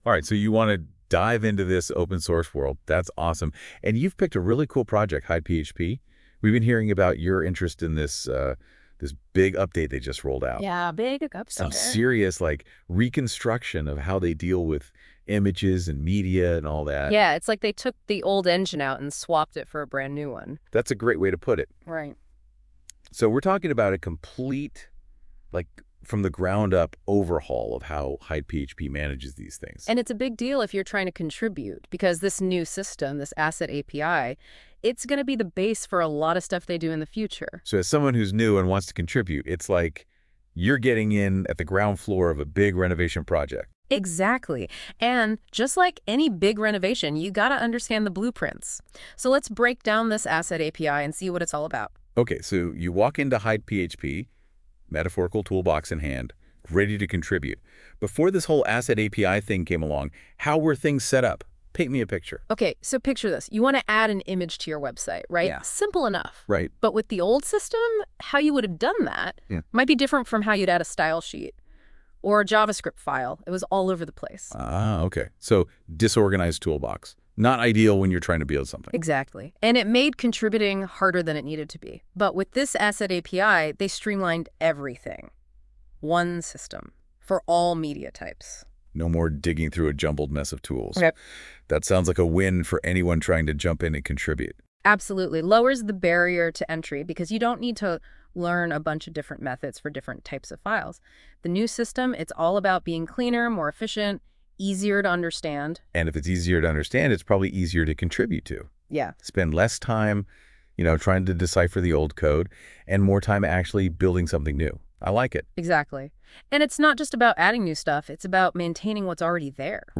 Listen to this AI-generated podcast episode by NotebookLM by Google which was fed the commit logs.